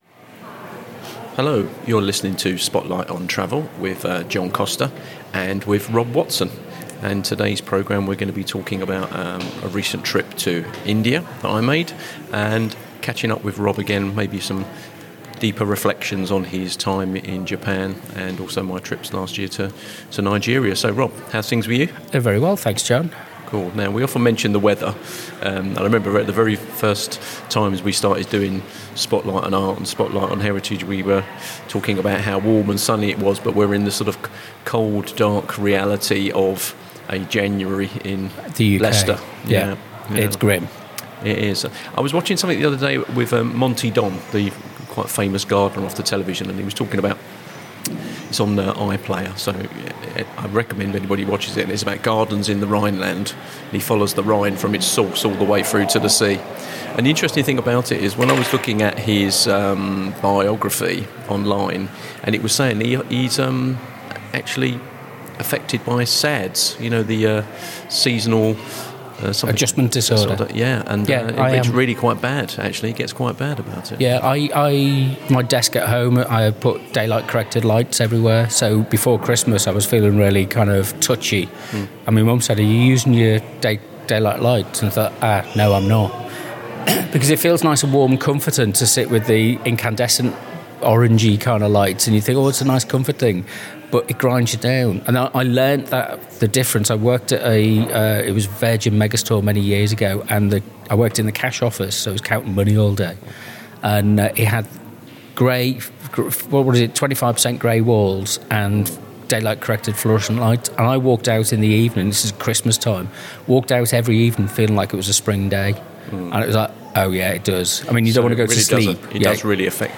In this edition of Spotlight on Travel, the conversation moves in a different direction.